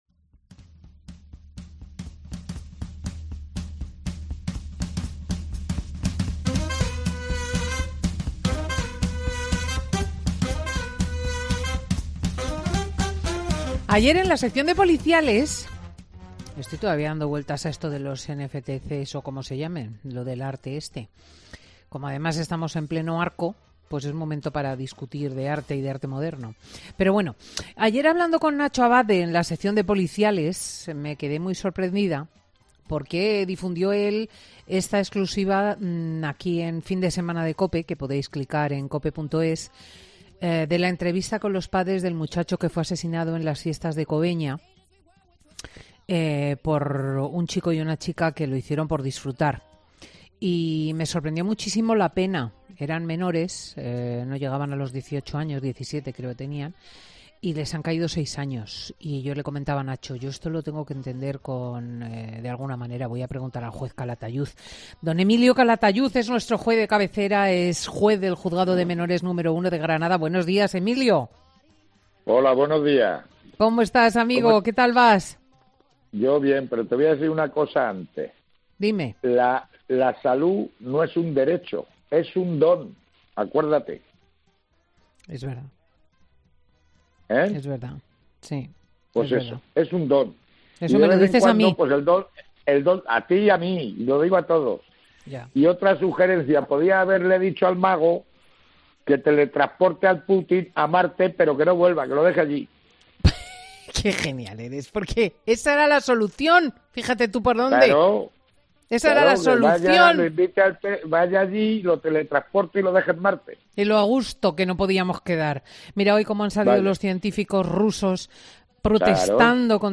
El juez de menores de Granada vuelve a Fin de Semana con Cristina para mostrarse muy crítico con las normas jurídicas relacionadas con los jóvenes y la...